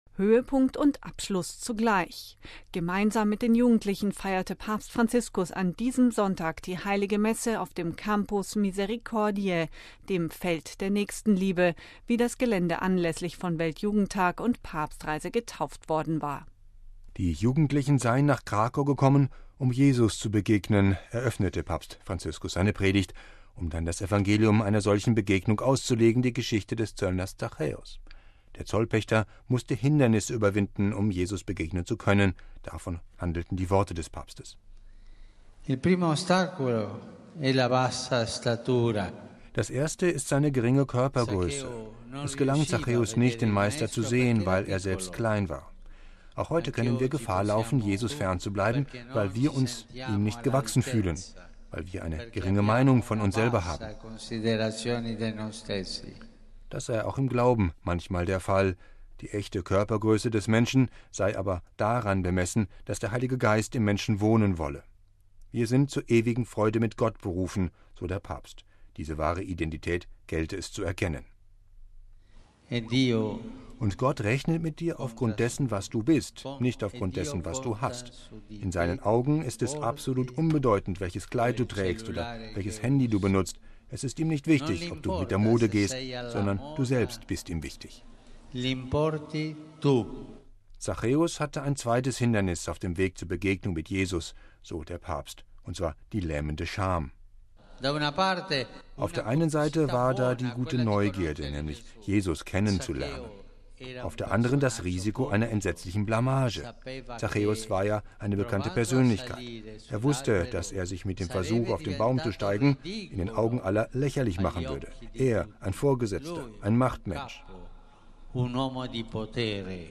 Höhepunkt und Abschluss zugleich: Gemeinsam mit den Jugendlichen feierte Papst Franziskus an diesem Sonntag die heilige Messe auf dem „Campus Misericordiae“, dem „Feld der Nächstenliebe“, wie das Gelände anlässlich von WJT und Papstreise getauft worden war.